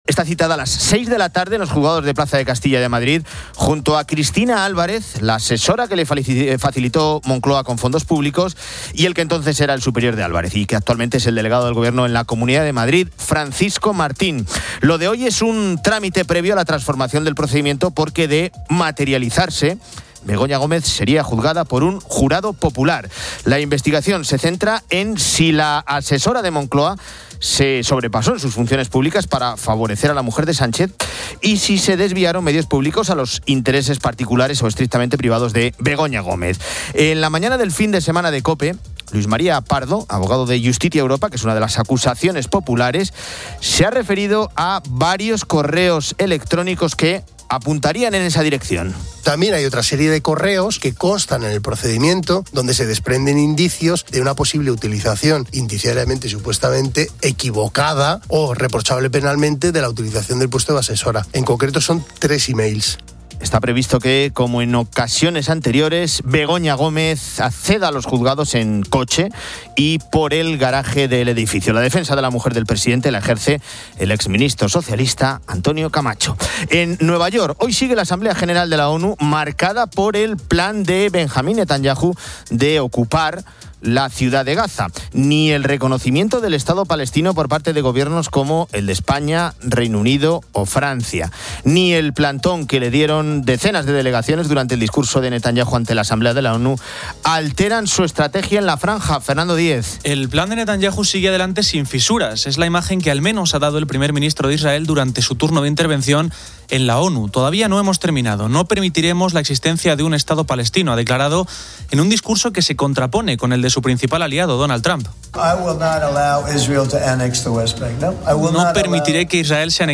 Fin de Semana 10:00H | 27 SEP 2025 | Fin de Semana Editorial de Cristina López Schlichting. Antonio Jiménez repasa la actualidad con la directora del programa.